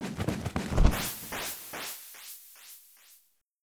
snd_martlet_takeoff.wav